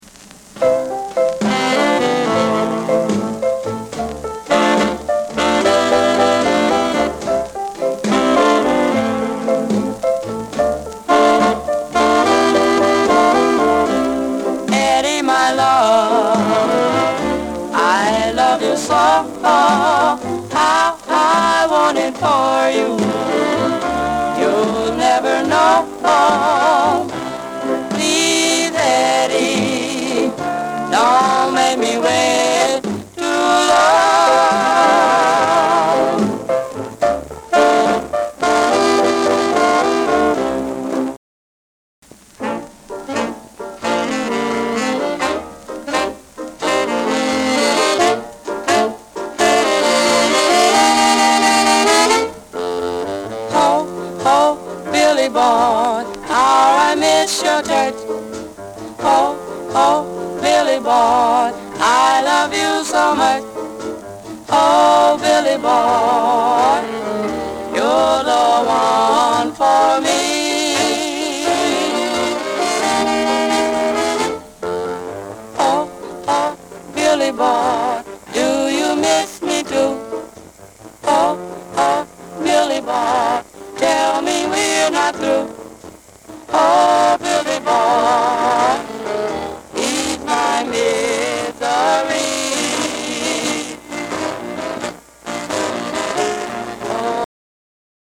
OLDIES 45R&B / SOUL オリジナルは1958年リリース。女性R&B・ボーカル・デュオ